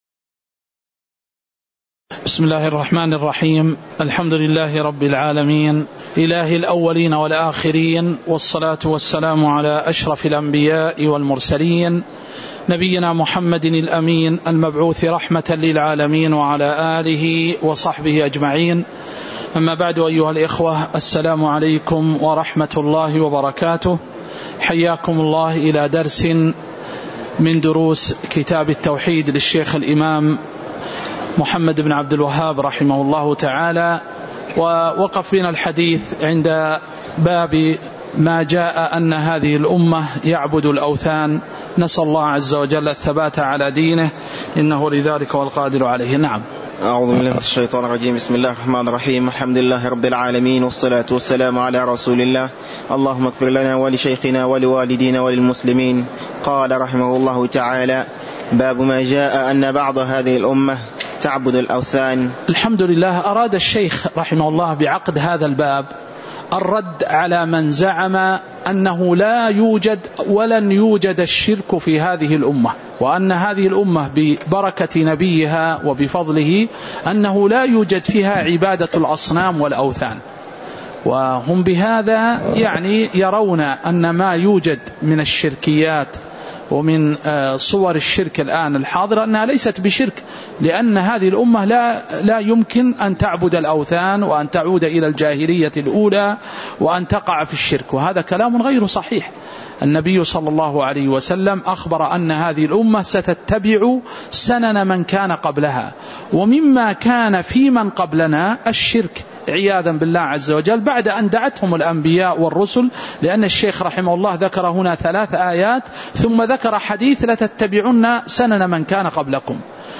تاريخ النشر ٢٤ شعبان ١٤٤٠ هـ المكان: المسجد النبوي الشيخ